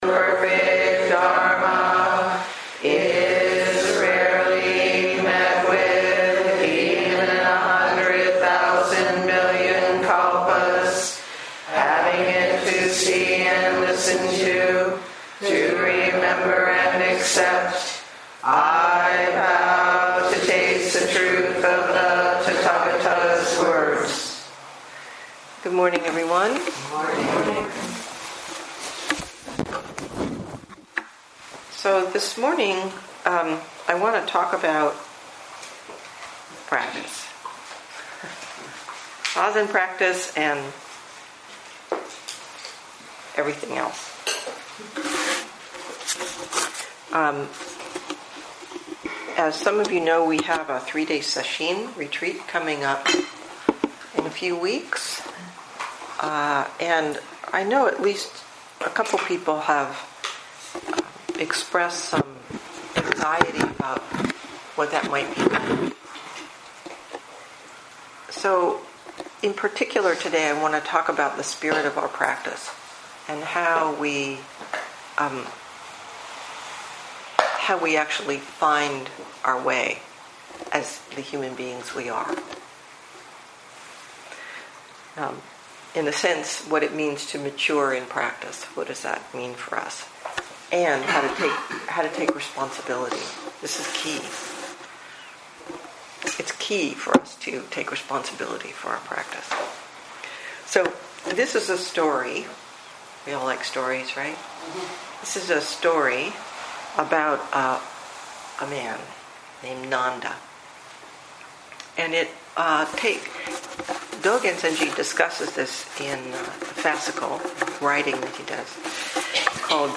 2019 in Dharma Talks